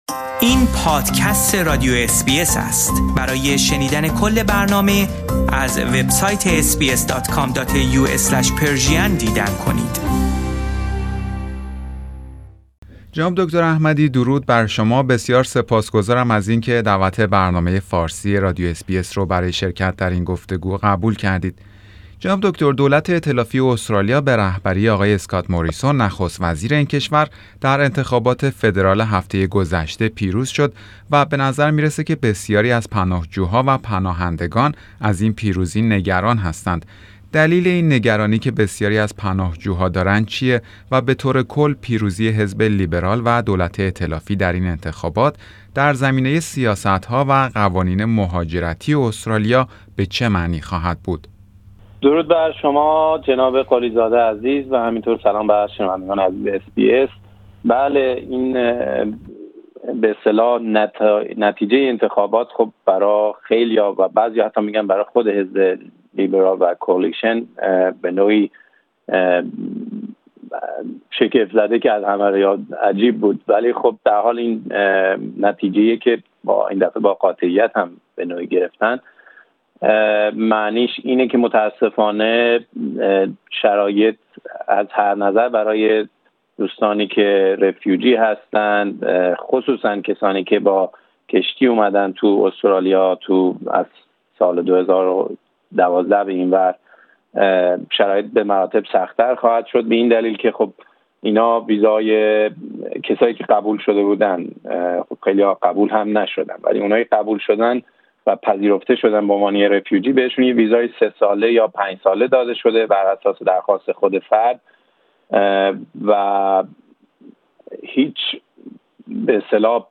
برنامه فارسی رادیو اس بی اس در همین باره گفتگویی داشته است